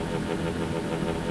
Index of /server/sound/weapons/tfa_cso/stunrifle